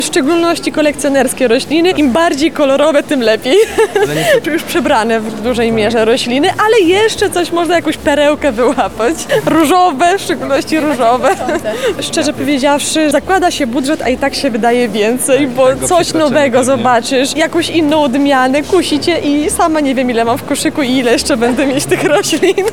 W miniony weekend (28 i 29 marca) Targi Lublin gościły Festiwal Roślin, czyli największą imprezę dla miłośników zieleni w Polsce.